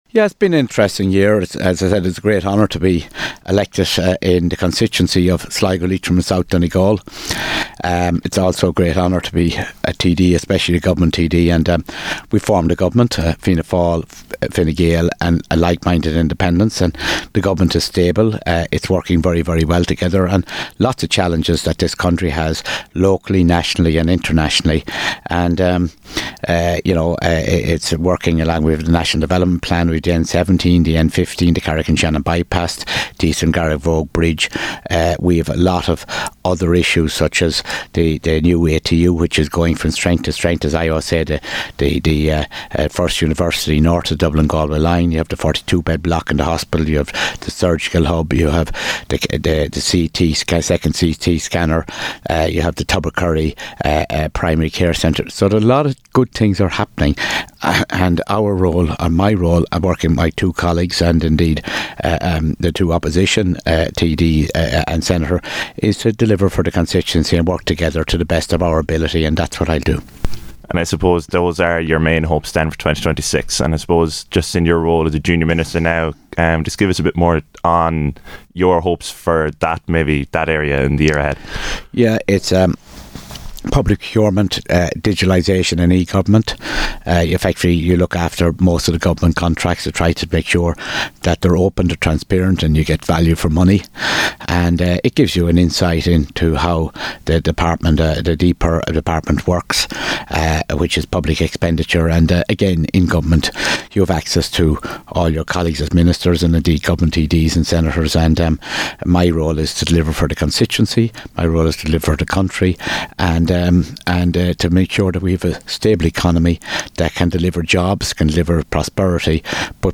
Speaking to Ocean FM News, he has reflected on the past year and looked ahead to 2026.…